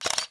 Camera_Shutter.wav